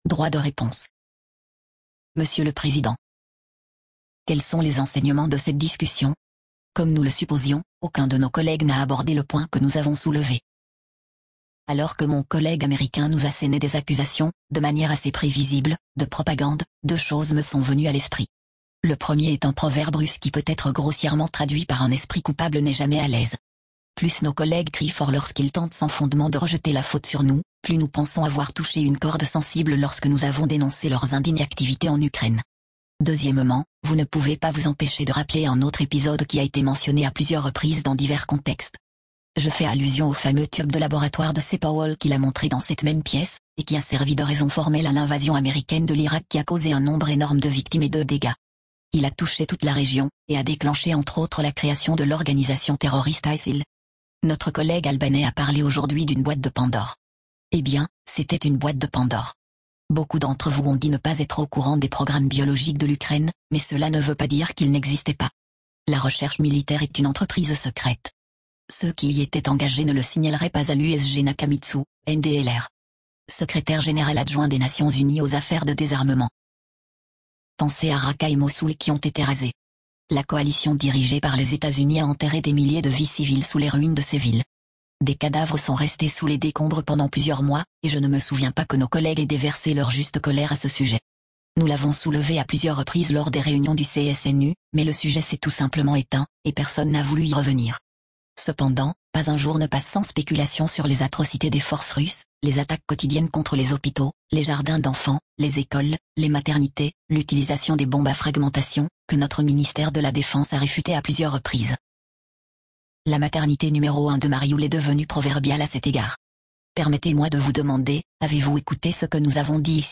Voici la déclaration du Représentant permanent de la Russie, Vassily Nebenzia, lors d'une réunion d'information du Conseil de sécurité des Nations unies (CSNU) sur les laboratoires biologiques en Ukraine.